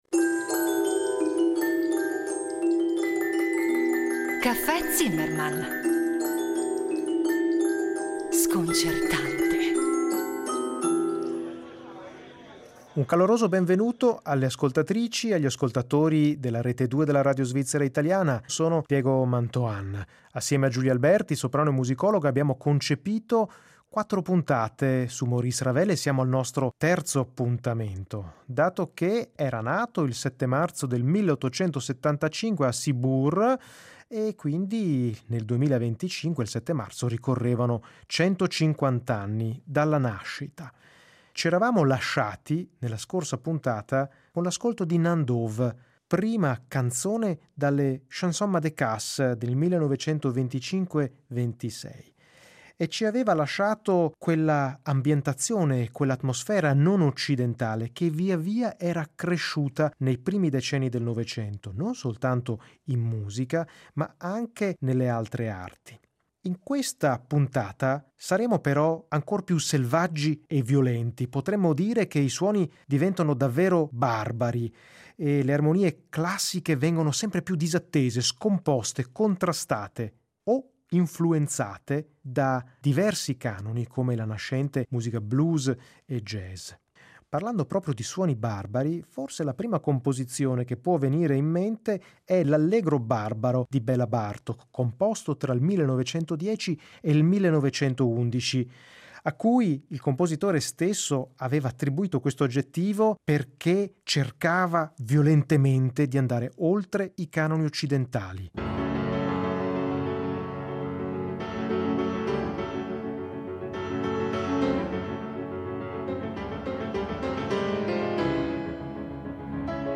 E poi la musica, ovviamente, dal primitivismo più autentico che parte da suggestioni extroccidentali per immergere l’ascoltatore in un’atmosfera distante, in un nuovo mondo sonoro, fino alle contaminazioni blues.